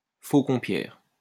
Faucompierre (French pronunciation: [fokɔ̃pjɛʁ]